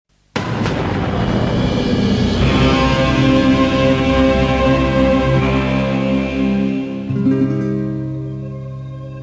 Приведено вступление арии